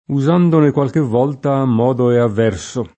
modo [m0do] s. m. — in gf. tanto unita quanto divisa la locuz. a modo (o ammodo) e il suo dim. a modino (o ammodino), indicanti diligenza e discrezione, con uso assoluto — solo in gf. divisa a modo, in altri valori, con qualche complem.: una figura vestita a modo di sacerdote [una fig2ra veSt&ta a mm0do di Sa©erd0te] (Cellini); usandone qualche volta a modo e a verso [